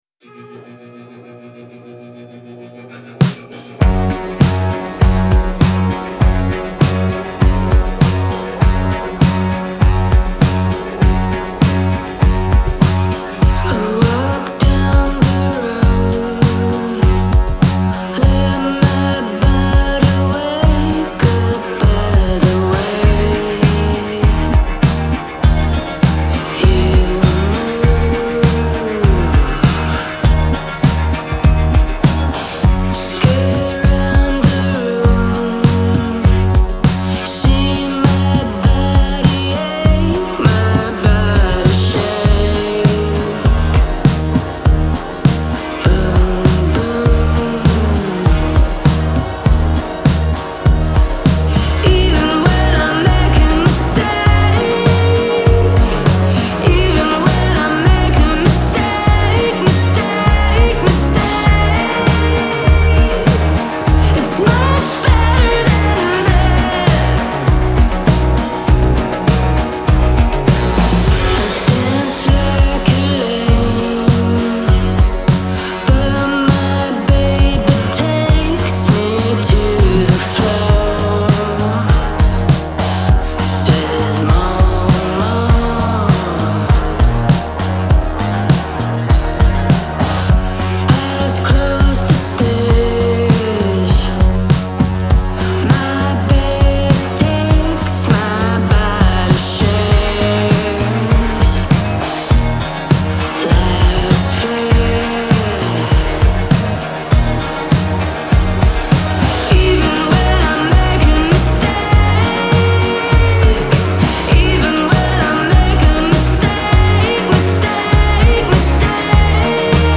Genre : Alternative, Indie, Pop, Rock